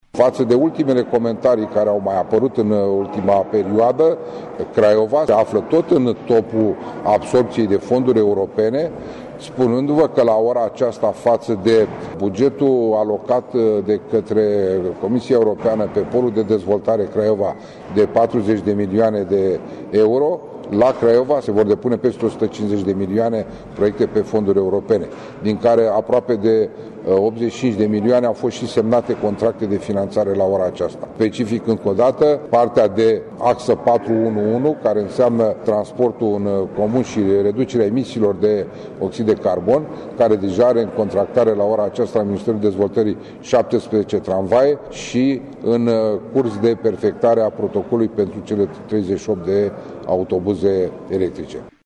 Mihail Genoiu, primarul municipiului Craiova: https